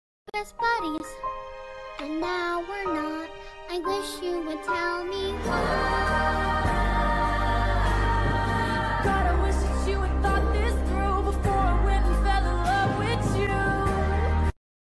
sad